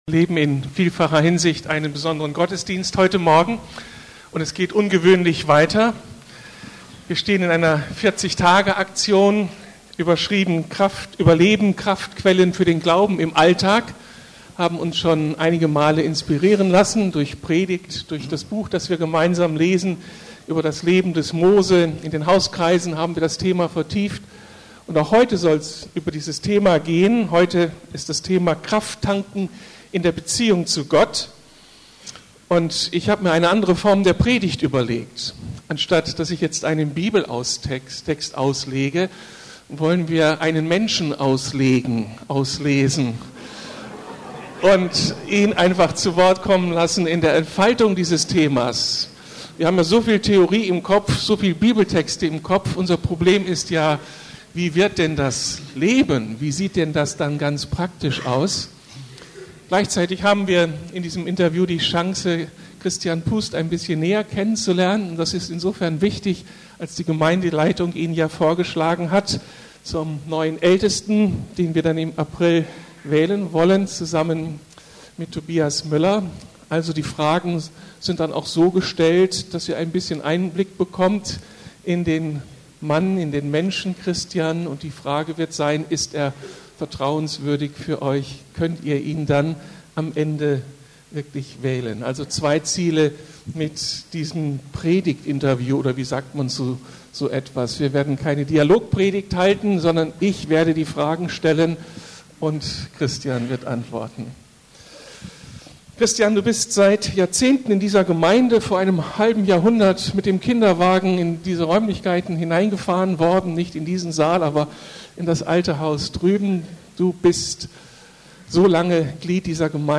Kraft tanken in der Beziehung zu Gott ~ Predigten der LUKAS GEMEINDE Podcast